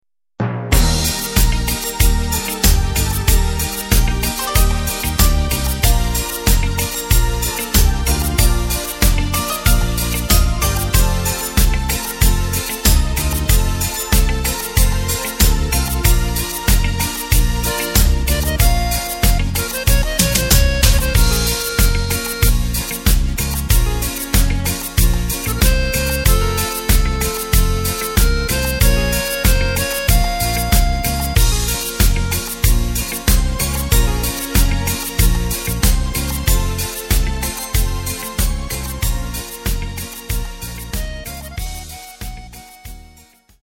Takt:          4/4
Tempo:         94.00
Tonart:            Bb
Schlager aus dem Jahr 2006!
Playback mp3 Demo